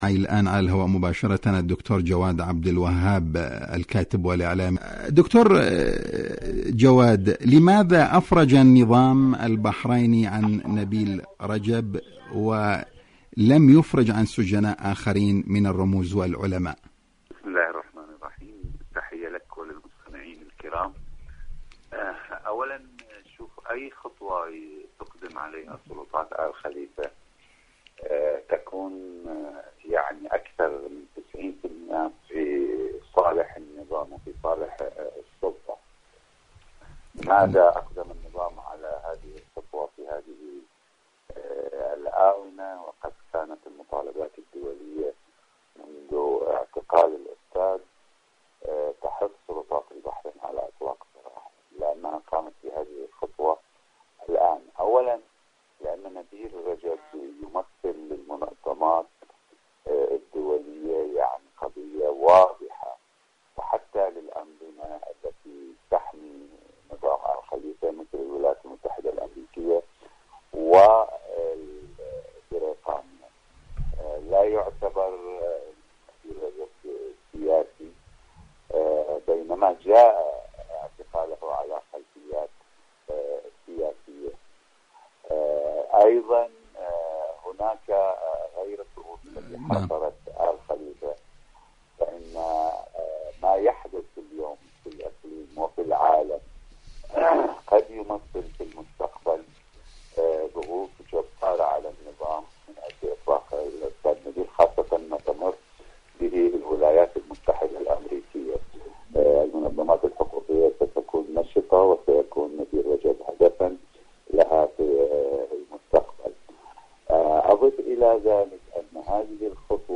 مقابلة إذاعية